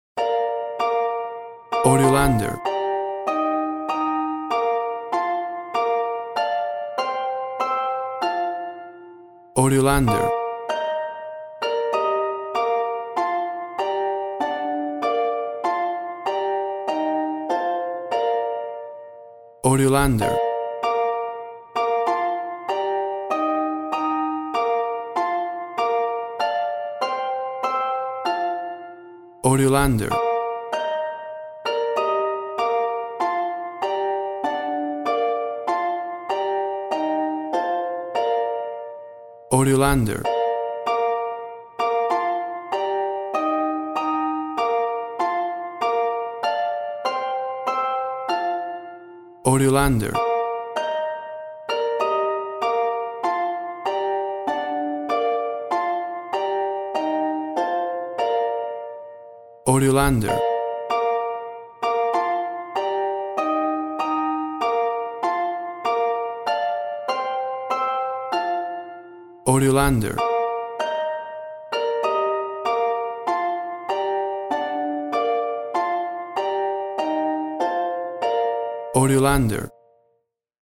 A traditional harp rendition
Full of happy joyful festive sounds and holiday feeling!.
WAV Sample Rate 16-Bit Stereo, 44.1 kHz
Tempo (BPM) 100